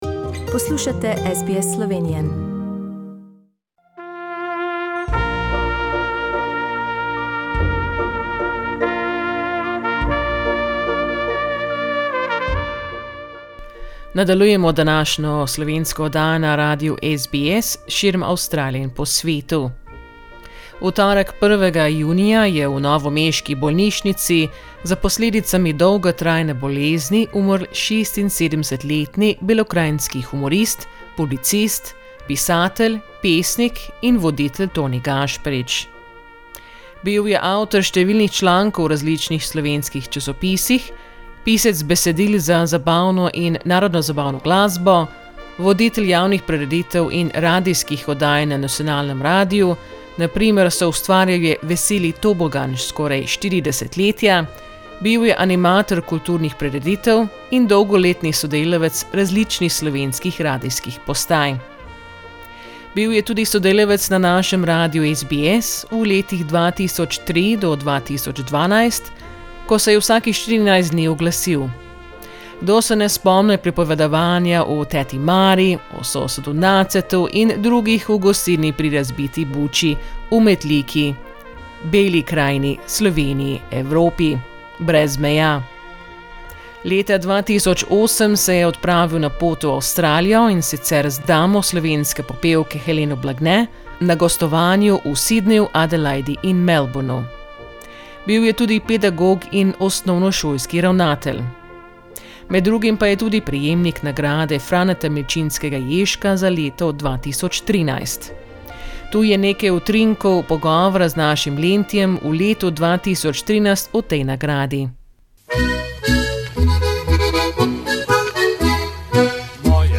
Tu je nekaj utrinkov pogovora